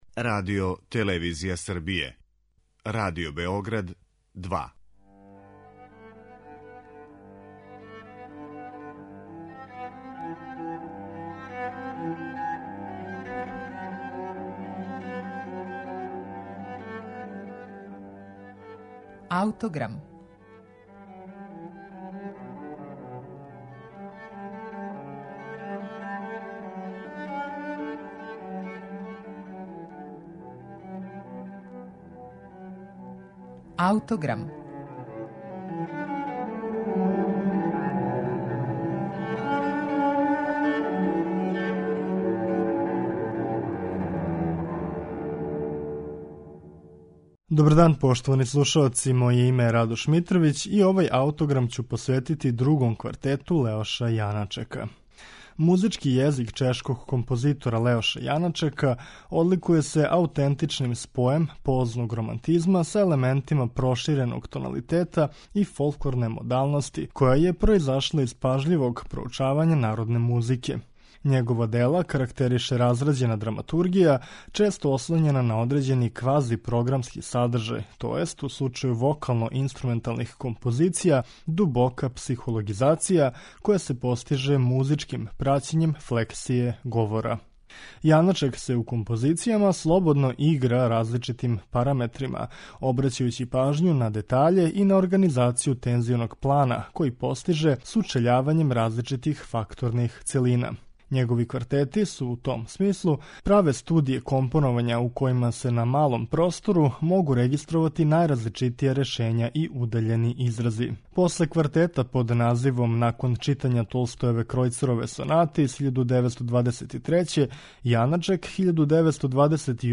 Други квартет Леоша Јаначека
Квартет, необичне драматургије и израза, носи назив Интимне странице, писан је као поруџбина чувеног Чешког квартета и упечатљиво заокружује стваралаштво овог чешког уметника.
Други квартет Леоша Јаначека слушаћете у извођењу квартета Хаген.